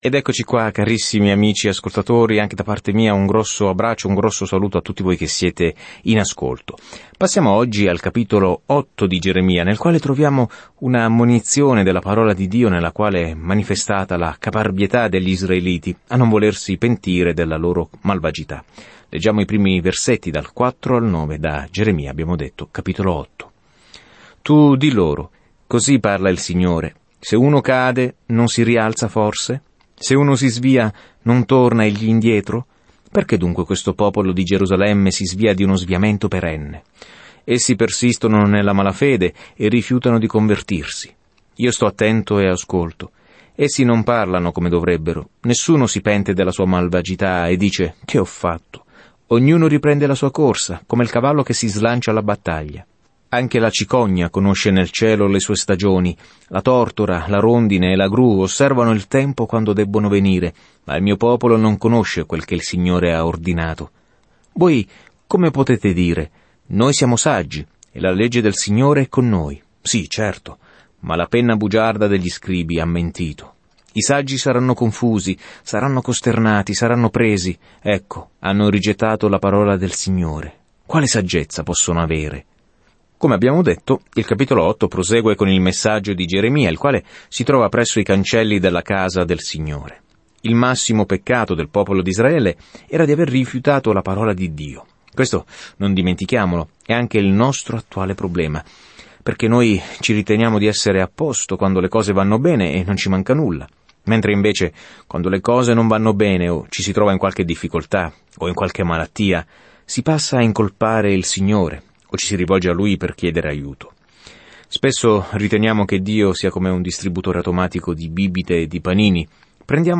Scrittura Geremia 8 Geremia 9 Geremia 10 Giorno 6 Comincia questo Piano Giorno 8 Riguardo questo Piano Dio ha scelto Geremia, un uomo dal cuore tenero, per trasmettere un messaggio duro, ma le persone non ricevono bene il messaggio. Viaggia ogni giorno attraverso Geremia mentre ascolti lo studio audio e leggi versetti selezionati della parola di Dio.